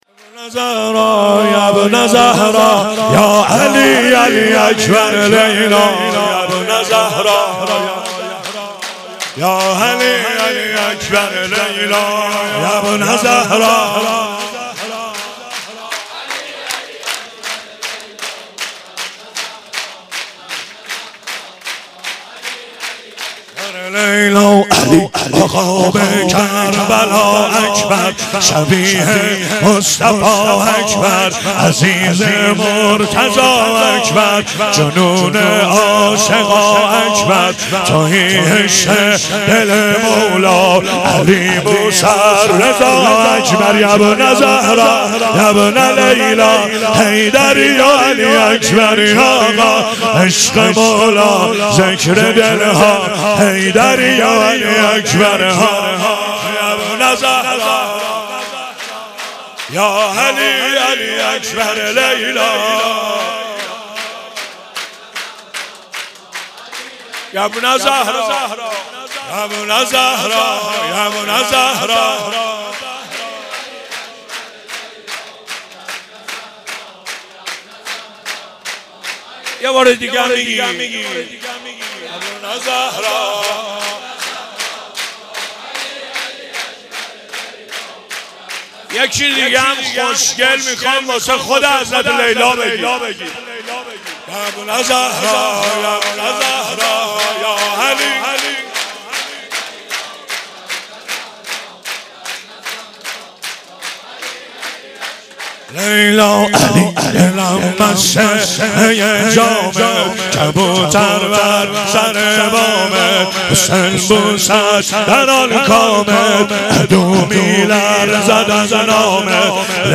شور مولودی